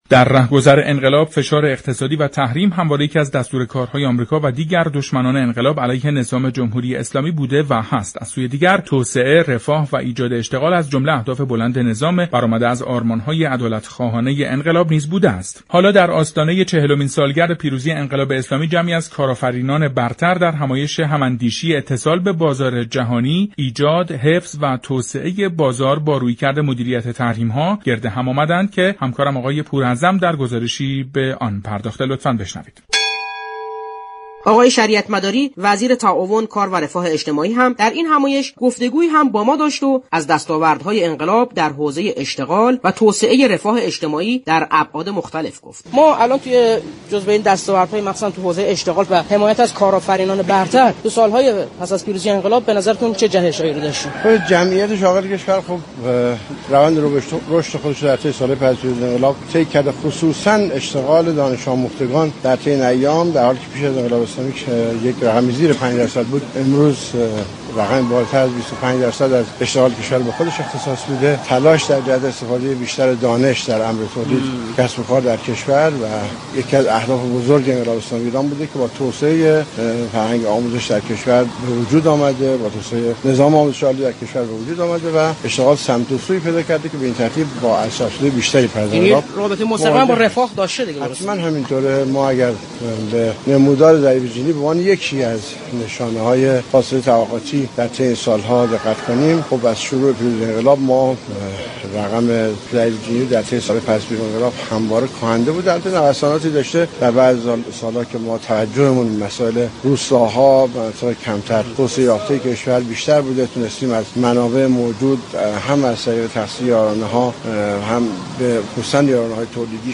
«محمد شریعتمداری» وزیر تعاون، كار و رفاه اجتماعی در برنامه جهان سیاست رادیو ایران گفت : تلاش برای كاهش فاصله طبقاتی در كشور یكی از رویكردهای اصلی انقلاب اسلامی ایران است